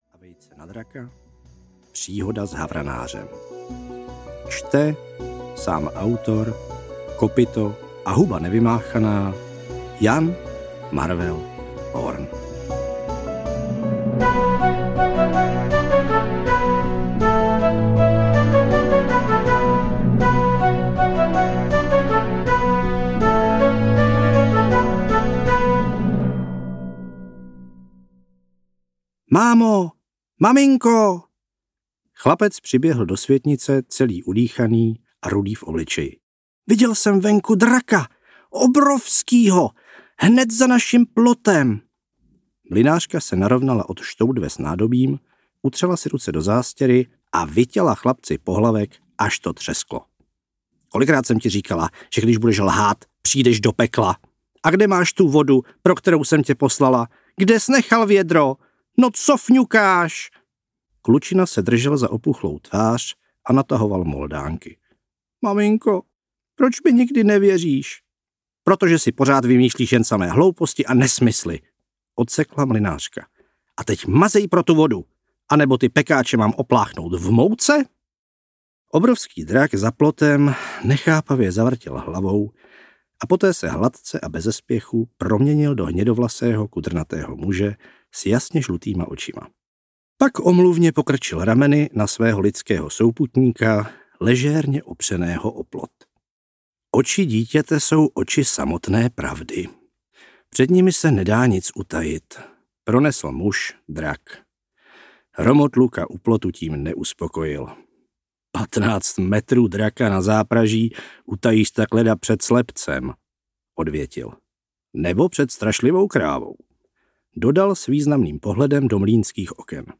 Ukázka z knihy
valard-prihoda-s-havranarem-audiokniha